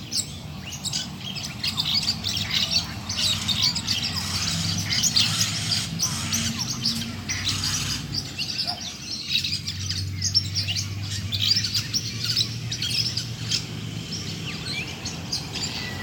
Ruf und Lautstärke
Er verfügt zwar über eine kräftige Stimme, nutzt diese aber meist nur für kurze Kontaktrufe („cluk-cluk“ oder ein metallisches Pfeifen).
Dennoch: Wenn sie erschrecken oder warnen, kann der Ruf schrill und durchdringend sein.
Hörbeispiel: Du möchtest wissen, wie der Schwarzgesichtsittich klingt? Hier findest du authentische Aufnahmen aus der Wildnis:
ruf-schwarzgesichtsittich-psephotellus-dissimilis.mp3